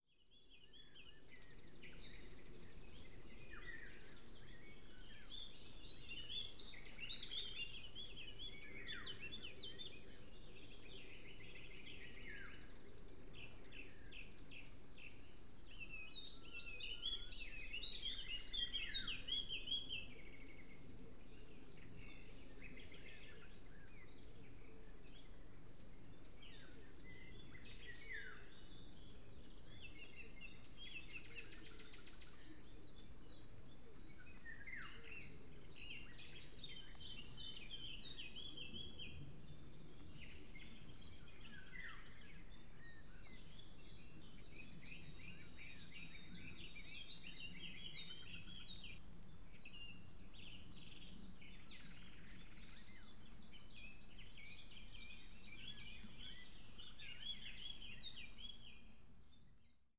Audioaufnahme vom 05.05.2019 05:10, Oberrheinische Tiefebene, südlich von Freiburg, große Waldinsel.